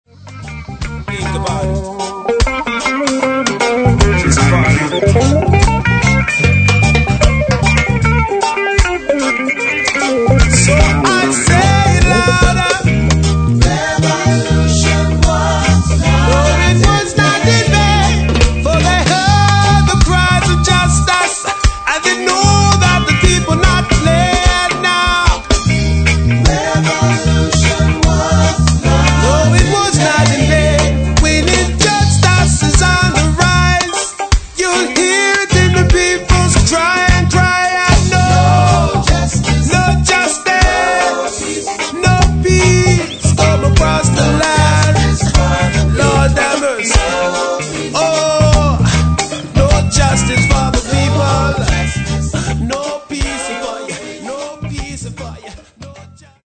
Marley style reggae